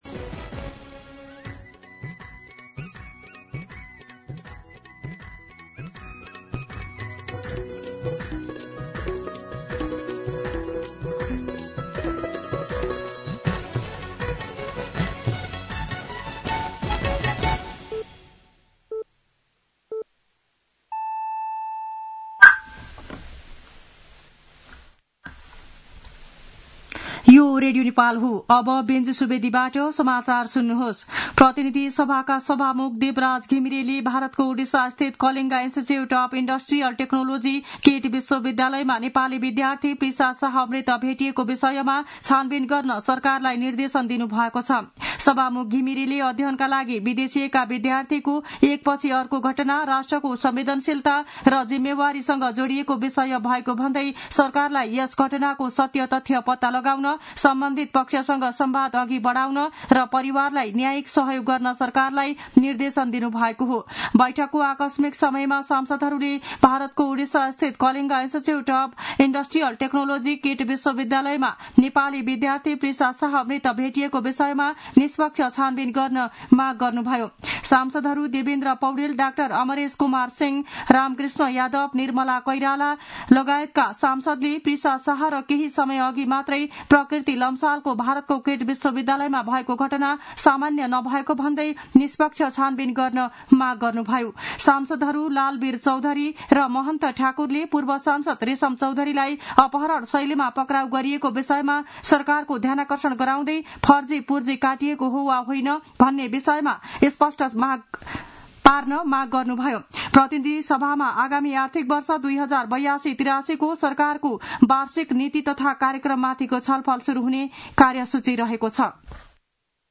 मध्यान्ह १२ बजेको नेपाली समाचार : २२ वैशाख , २०८२
12pm-Nepali-News-1-22.mp3